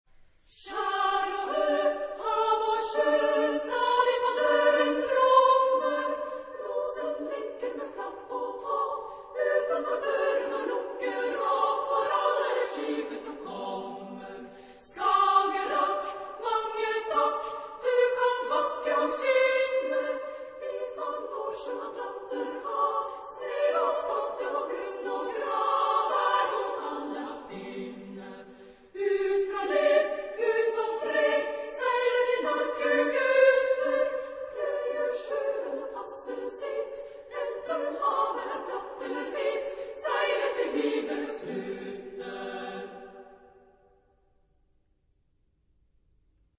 Genre-Style-Form: Popular ; Lied
Mood of the piece: fast
Type of Choir: SSA  (3 women voices )
Tonality: G major